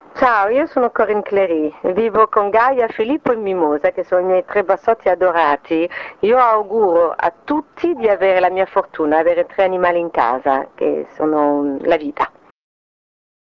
ASCOLTA GLI SPOT DI CORINNE CLERY
Spot 4 (Amore per gli animali)